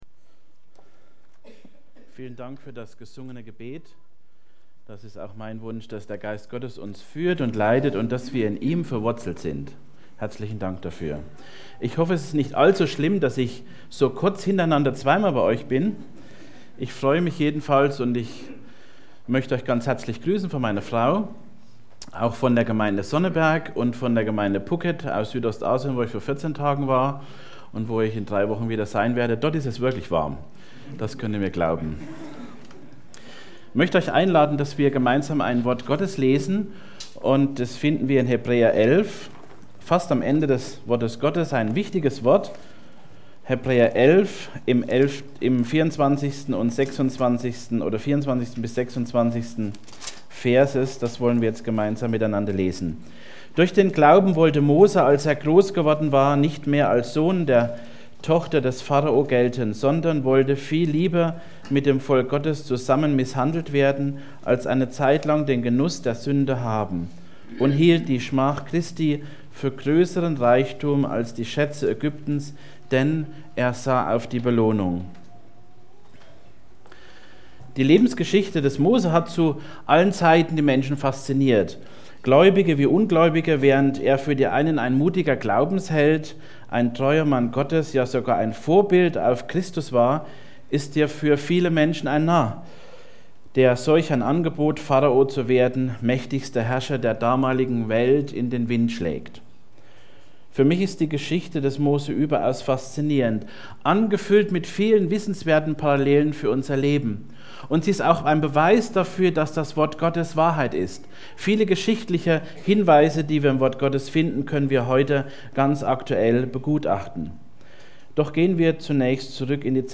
Predigten in mp3 - Blog